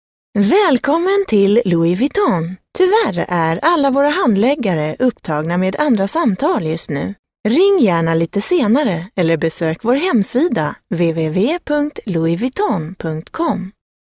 Native speaker Female 30-50 lat
Pleasant female Swedish voice.
Nagranie lektorskie